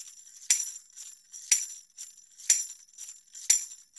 Pandereta_ ST 120_3.wav